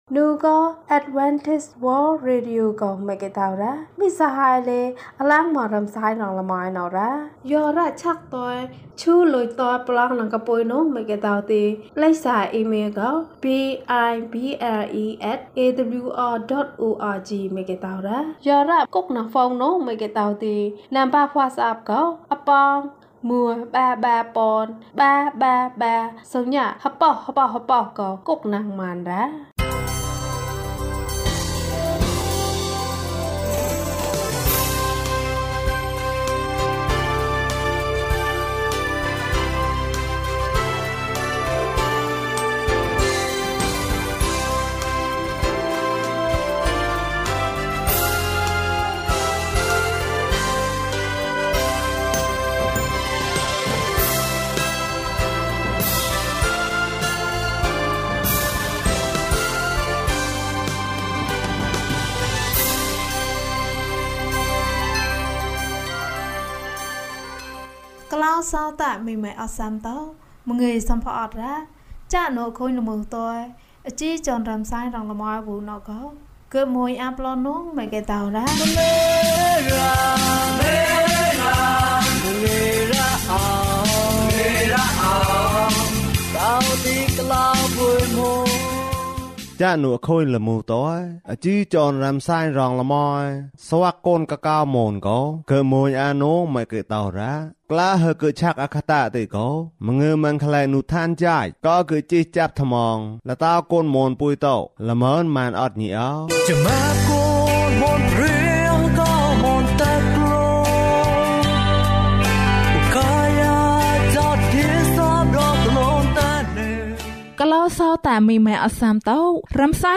ခရစ်တော်ထံသို့ ခြေလှမ်း။ ၄၀ ကျန်းမာခြင်းအကြောင်းအရာ။ ဓမ္မသီချင်း။ တရားဒေသနာ။